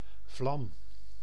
Ääntäminen
IPA : /ˈfleɪm/ US : IPA : [ˈfleɪm]